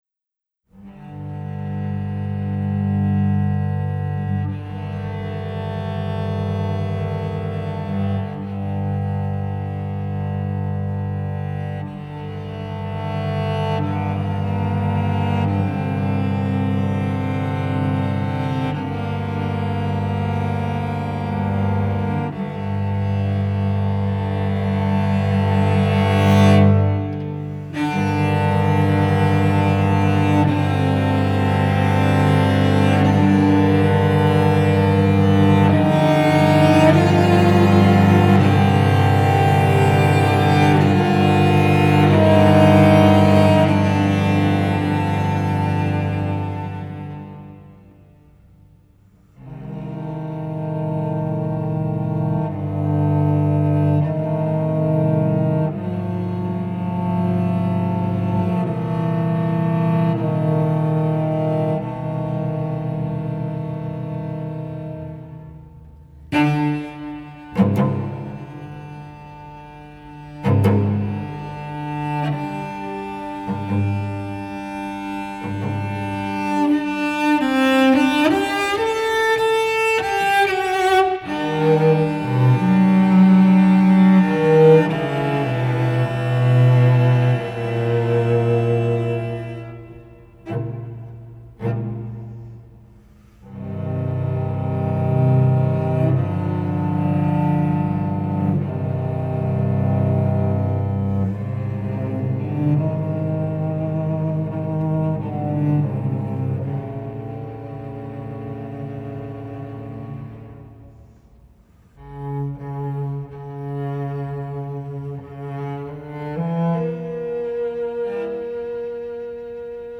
The chorale returns before a coda fades into silence.